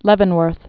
(lĕvən-wûrth)